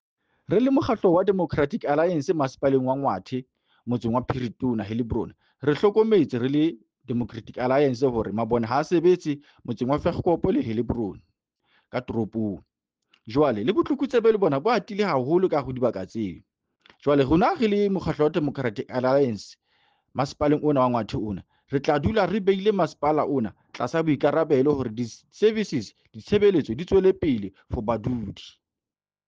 Sesotho soundbite by Cllr Joseph Mbele.